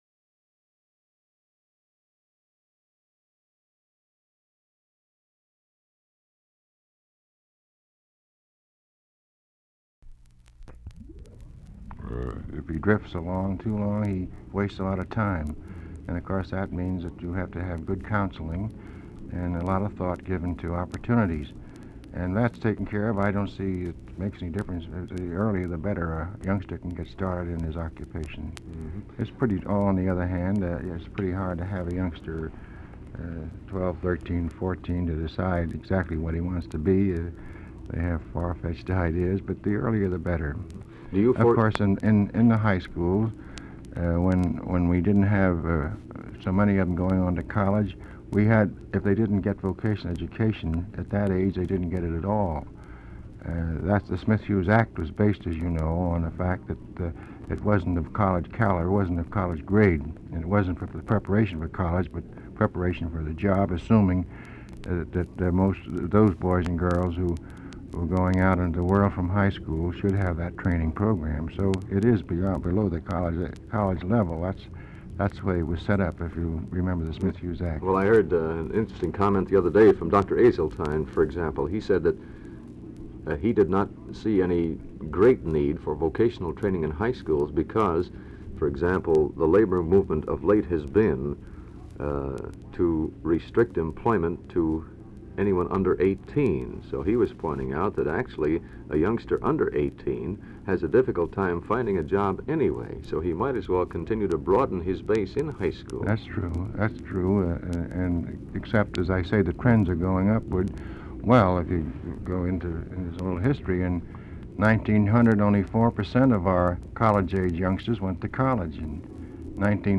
Form of original Phonograph record